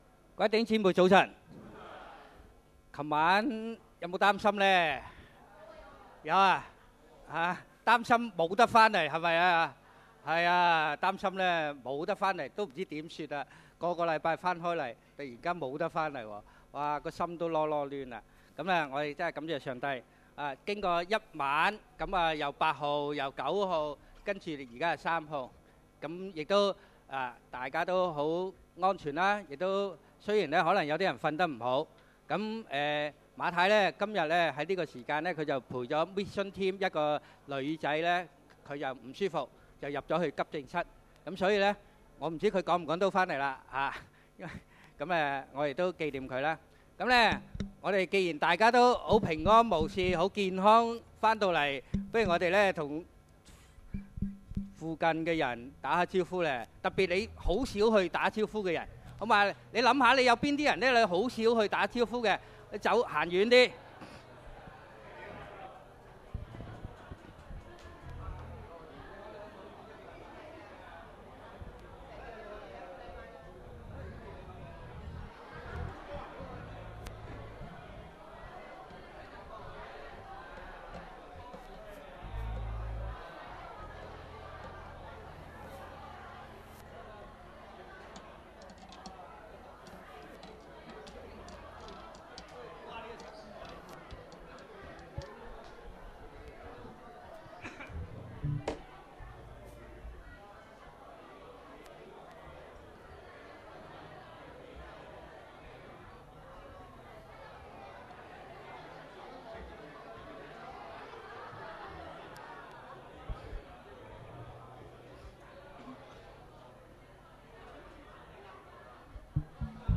Posted by admin on 八月 07 2009 | 下載, 銅管樂隊獻樂